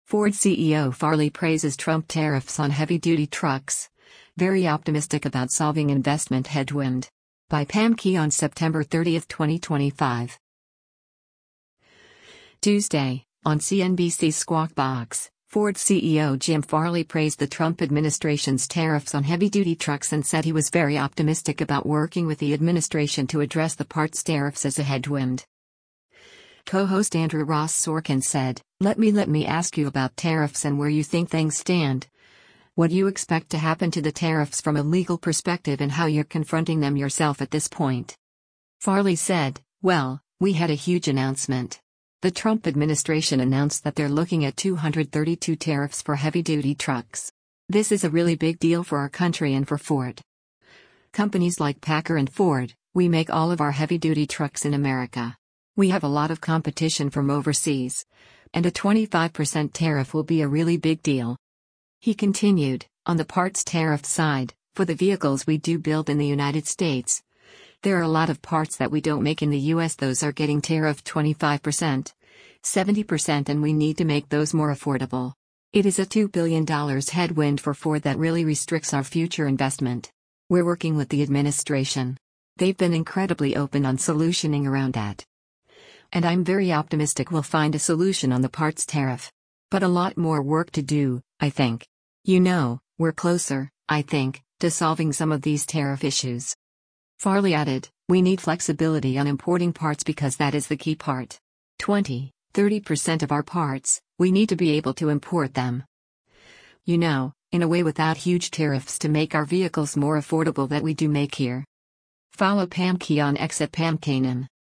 Tuesday, on CNBC’s “Squawk Box,” Ford CEO Jim Farley praised the Trump administration’s tariffs on heavy-duty trucks and said he was “very optimistic” about working with the administration to address the parts tariffs as a “headwind.”